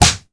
punch_slap_whack_hit_03.wav